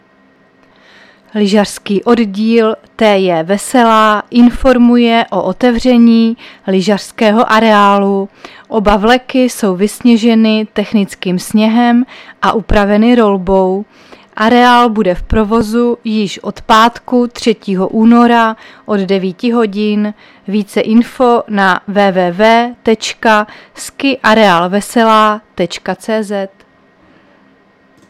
Záznam hlášení místního rozhlasu 2.2.2023